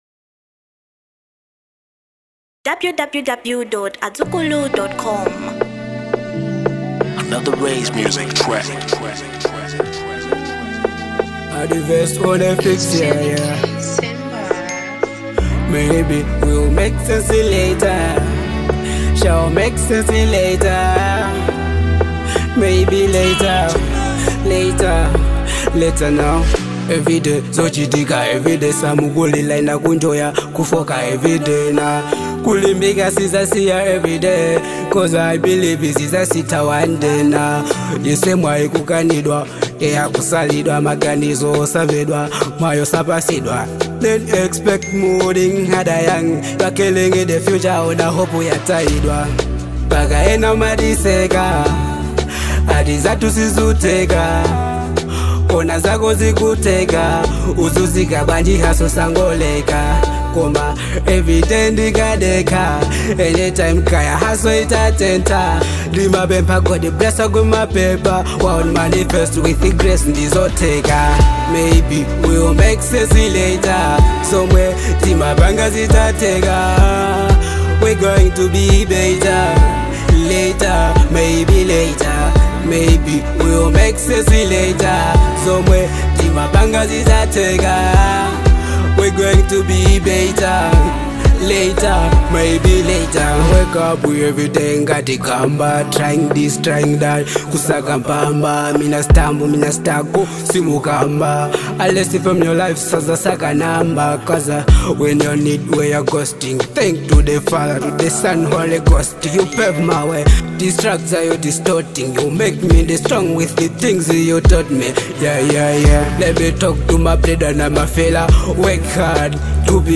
Genre Afro Pop